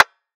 DDW2 PERC 2.wav